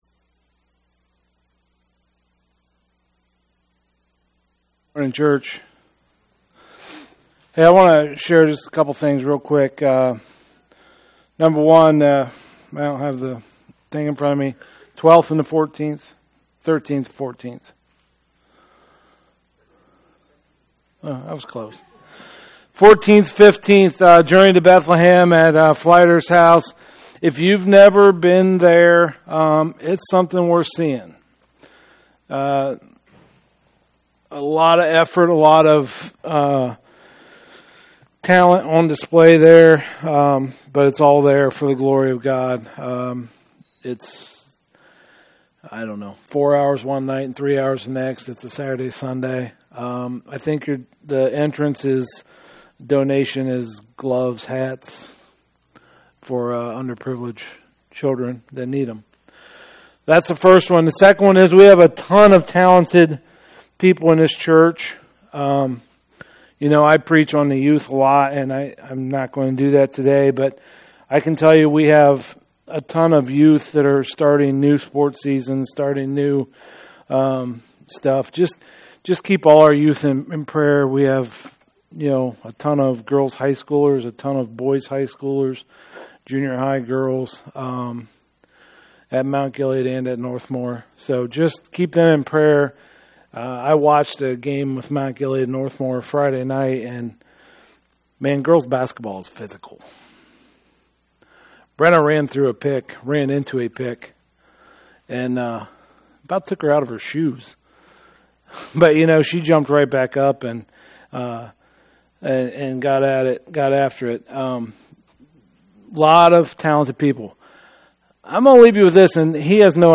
2024 Christmas Foretold Preacher